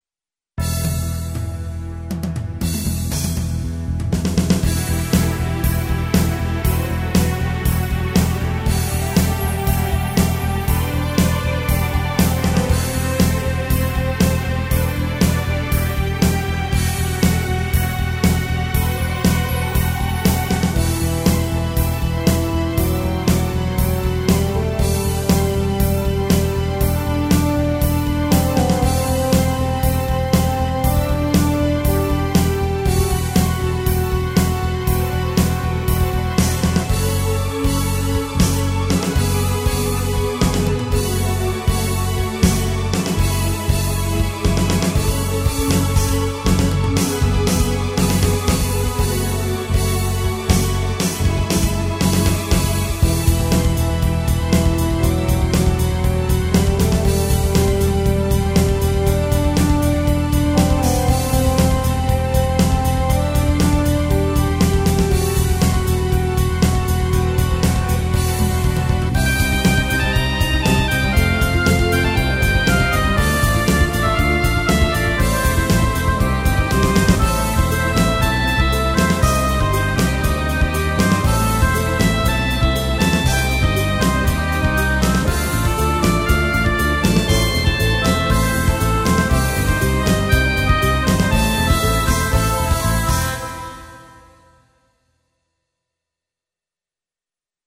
エレクトーンでゲーム音楽を弾きちらすコーナー。
使用機種：ELS-01C